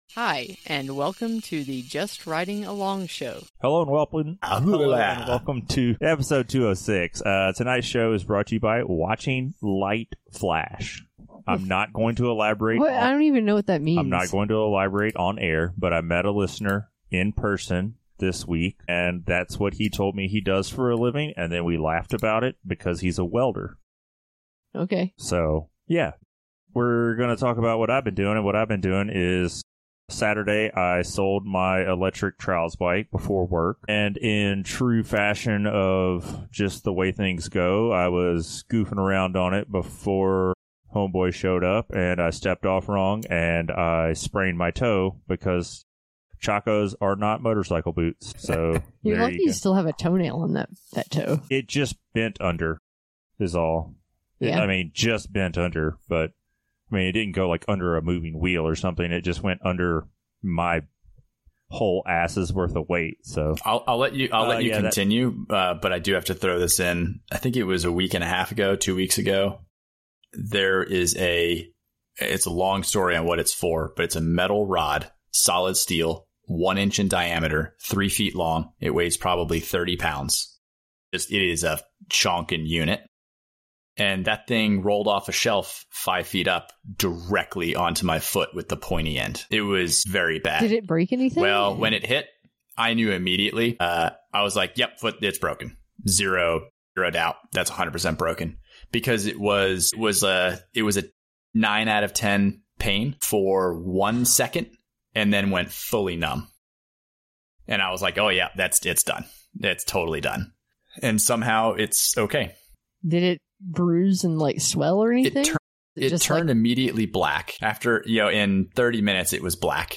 Listen in for entertaining conversation between long-time bike industry folks. We dish out our knowledge peppered with humor, rants, and strong opinions.